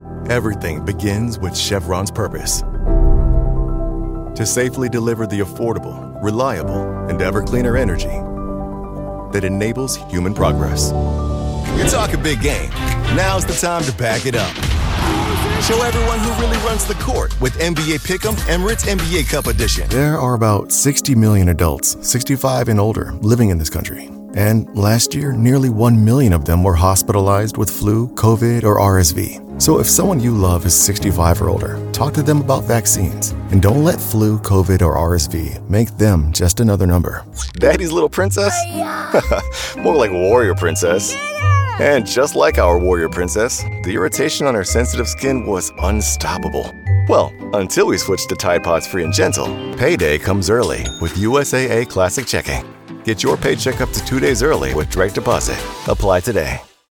Female TEENS , 20s British English (Native) Bright , Bubbly , Character , Children , Confident , Cool , Engaging , Friendly , Natural , Soft , Streetwise , Warm , Versatile , Young